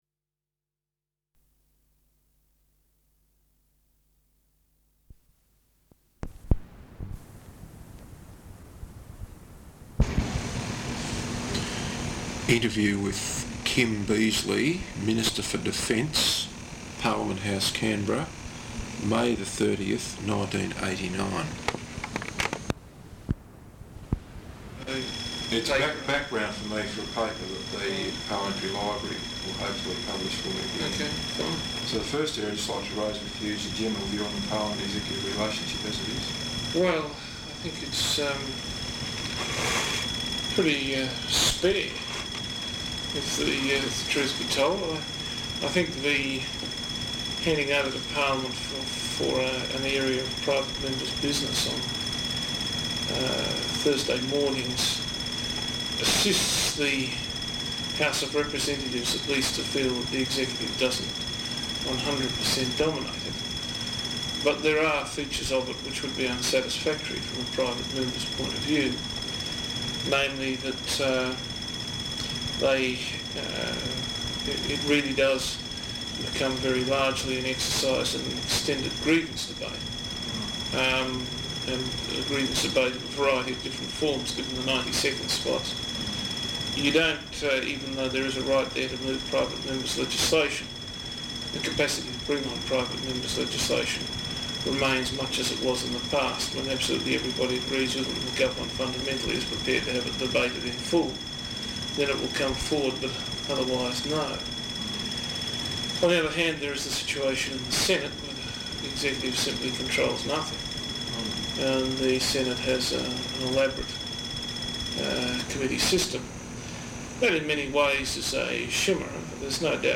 Interview with Kim Beazley Minister for Defence, Parliament House, Canberra May 30th, 1989.